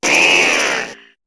Cri d' Abo dans Pokémon HOME .